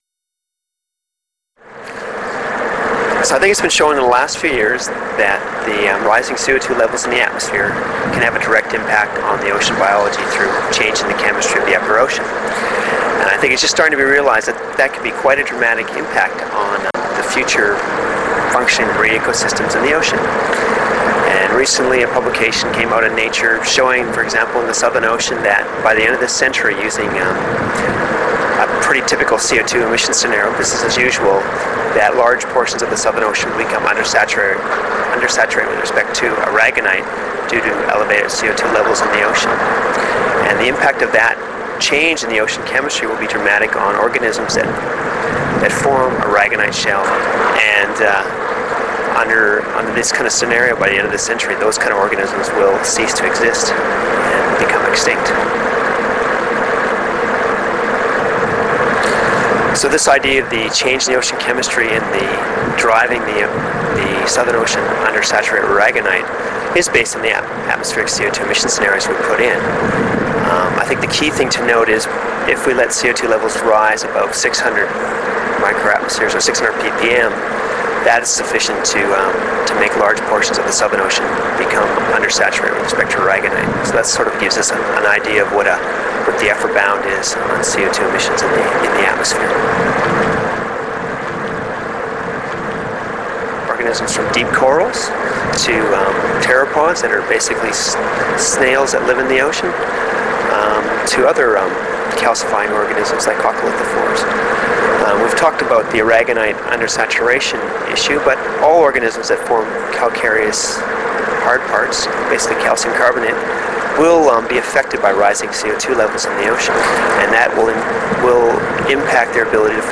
Scientist Interviews